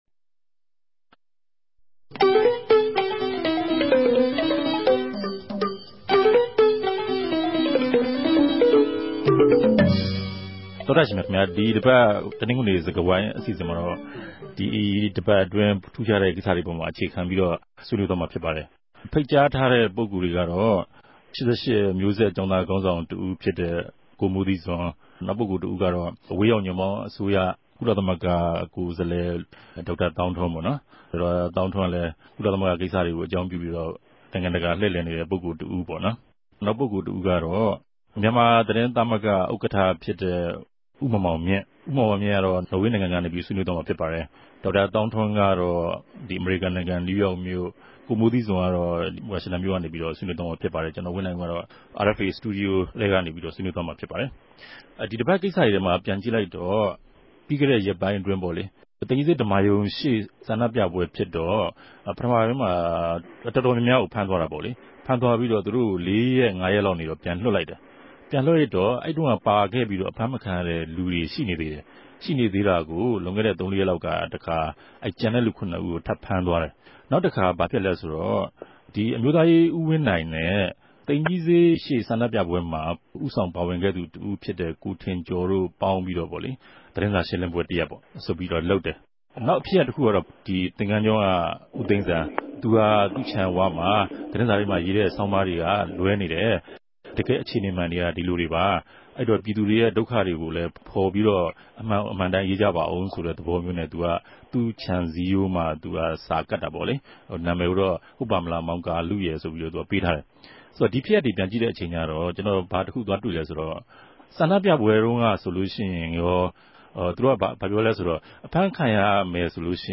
RFA ဝၝရြင်တန်႟ုံးခဵြပ် စတူဒီယိုကနေ
တယ်လီဖုန်းနဲႛ ဆက်သြယ်္ဘပီး၊ ပၝဝင် ဆြေးေိံြး သုံးသပ်ထားုကပၝတယ်၊၊